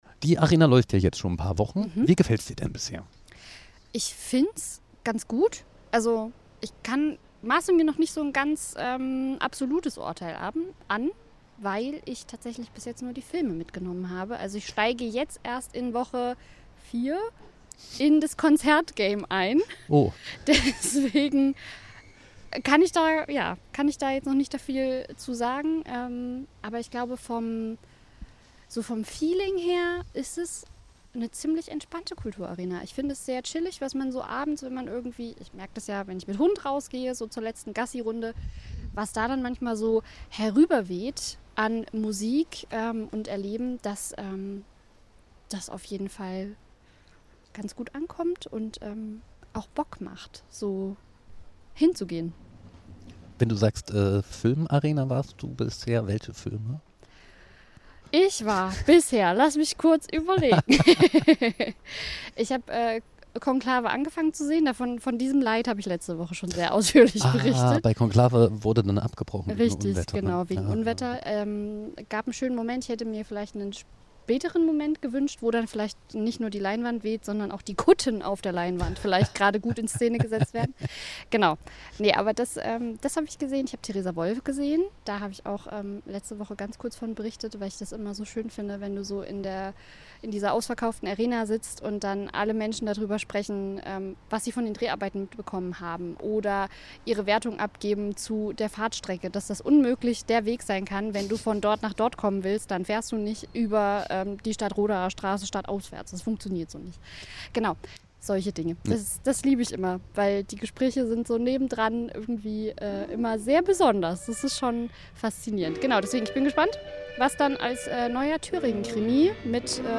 Jedes Jahr findet auf dem Theatervorplatz in Jena die Kulturarena statt und wir sind für euch Live dabei!
Liveberichterstattung vom Theatervorplatz.